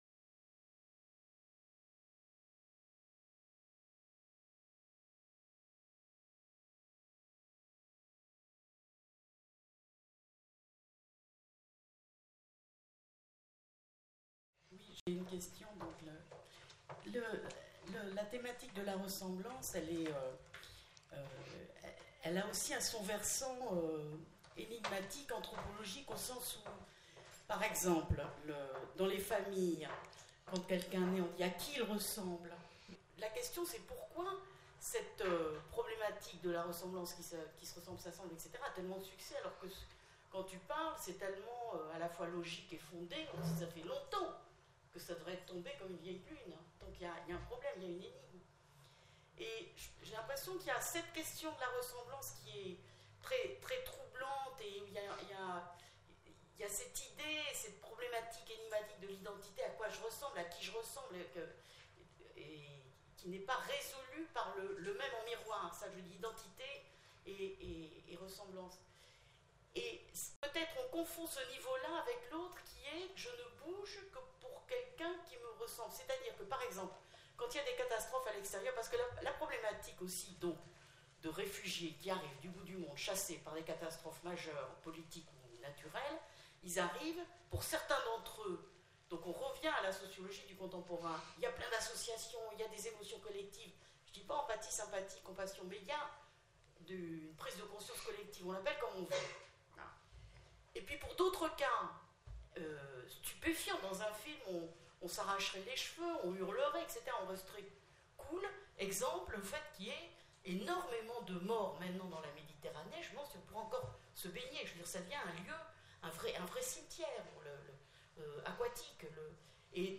Faut-il se ressembler pour s'assembler ? Questions du public | Canal U